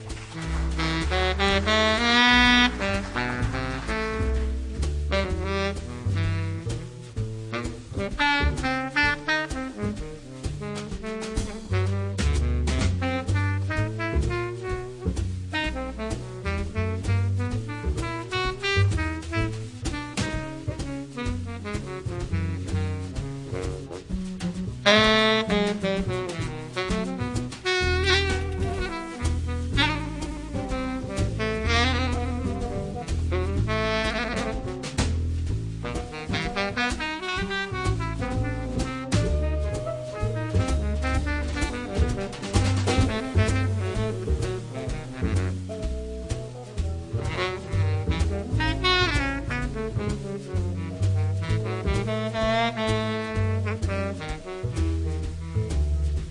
The Best In British Jazz
Recorded Assembly Rooms, Derby March 2005
A really great swinging piano-less quartet!